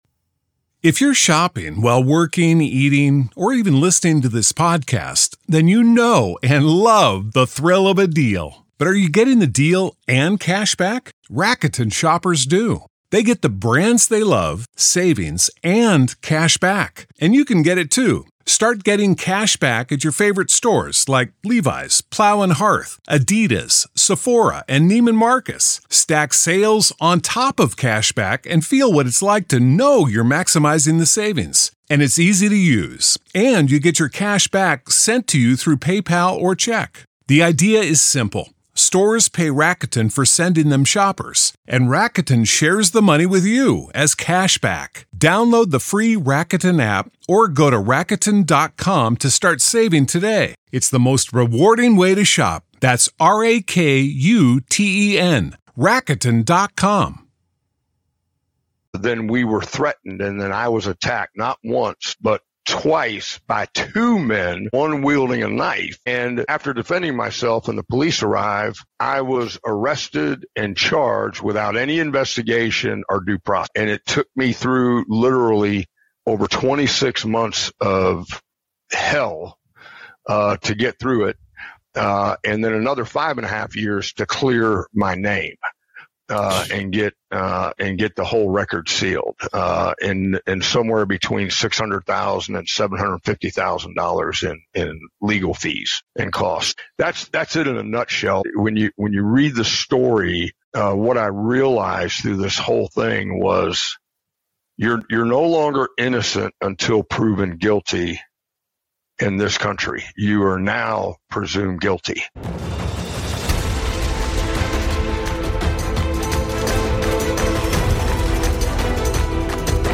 This episode dives deep into the realities faced by veterans, law enforcement, and anyone fighting to clear their name in a system that often presumes guilt before innocence. It’s a raw and emotional conversation about redemption, honor, and standing tall when the world turns against you.